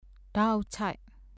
ဓအောက်ခြိုက် [dâ-ʔauʔcʰaiʔ ]子音字「ဓ」の名前。